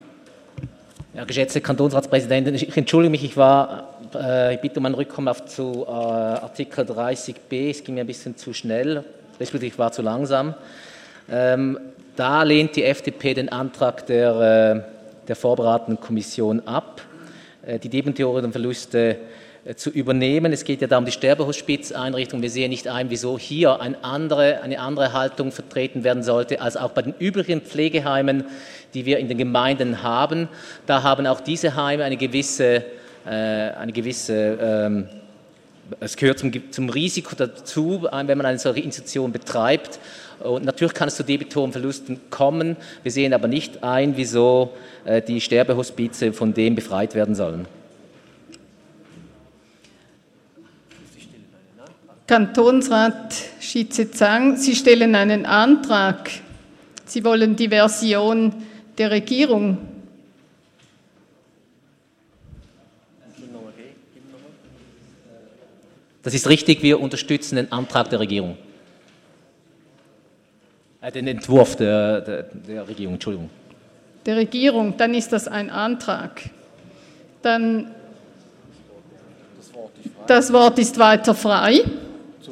17.9.2018Wortmeldung
Session des Kantonsrates vom 17. bis 19. September 2018